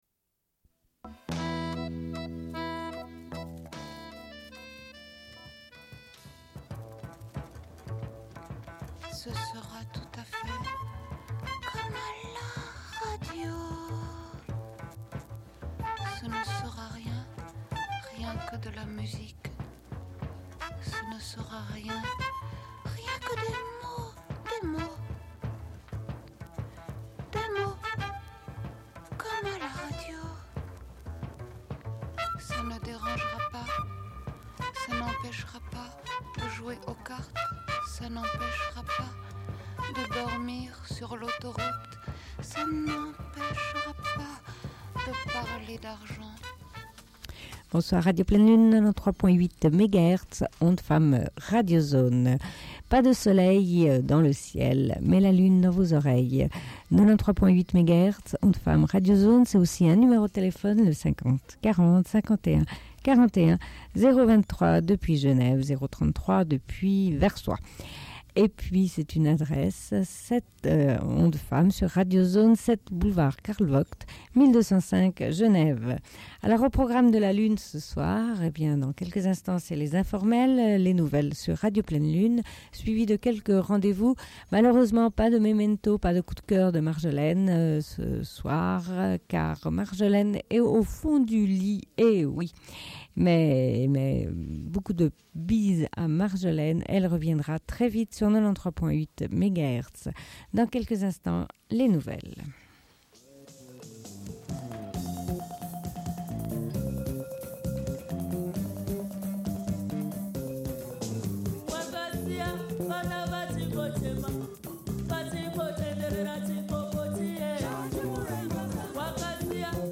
Bulletin d'information de Radio Pleine Lune du 18.05.1994 - Archives contestataires
Une cassette audio, face B29:18